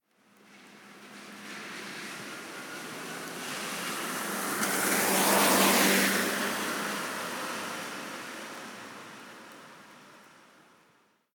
Coche pasando sobre charco 2
charco
coche
Sonidos: Agua
Sonidos: Transportes